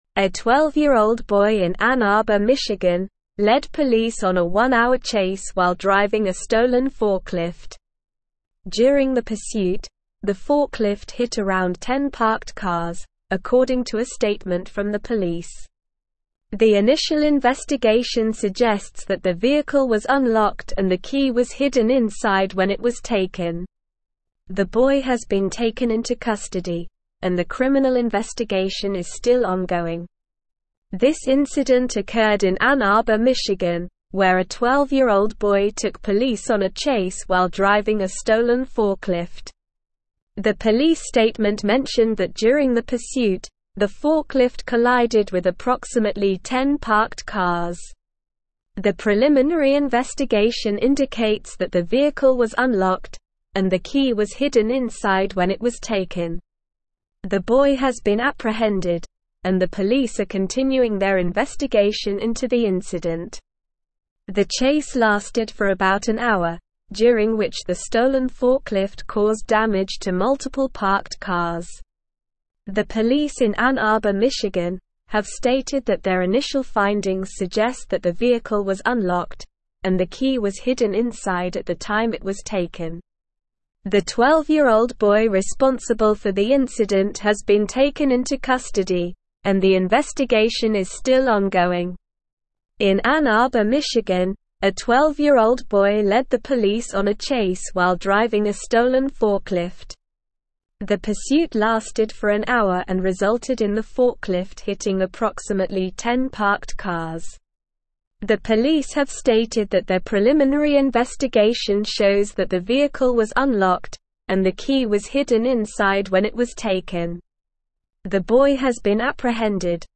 Slow
English-Newsroom-Advanced-SLOW-Reading-12-Year-Old-Boy-Steals-Forklift-Leads-Police-on-Chase.mp3